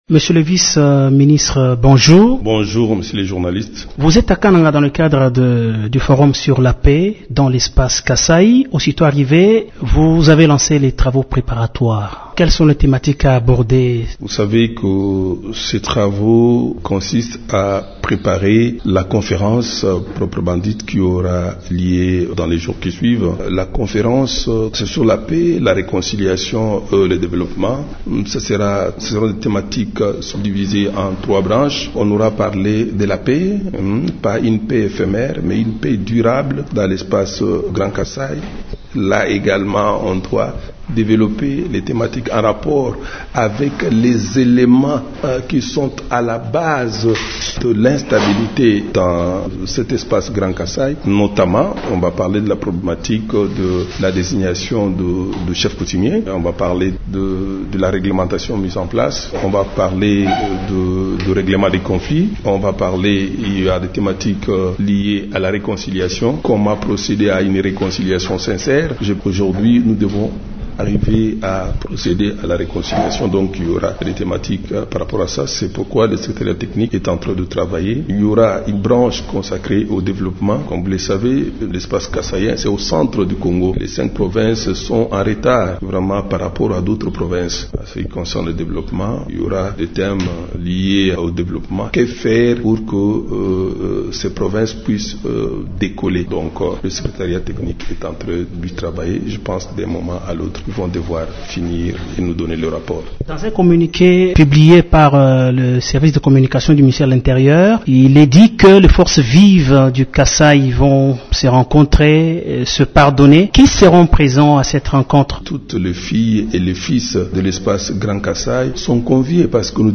Basile Olongo est l’invité de Radio Okapi jeudi 14 septembre.